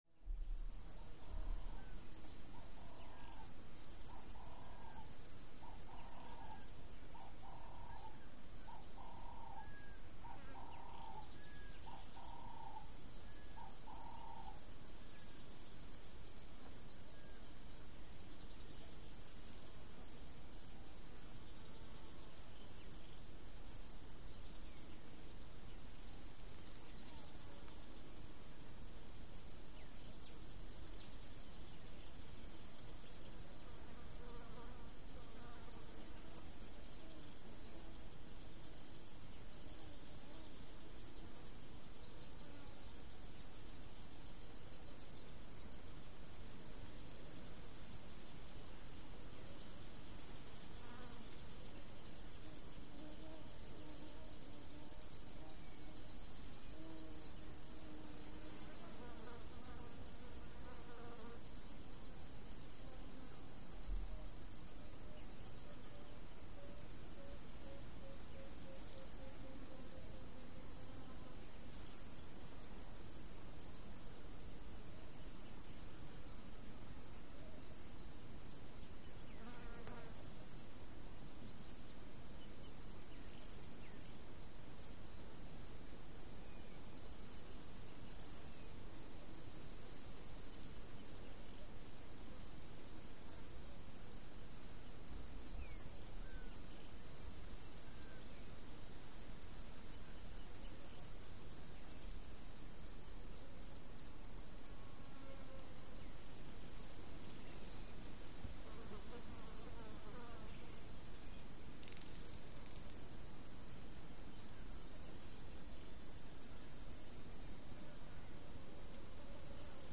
Steady Bird Calls And Various Insects.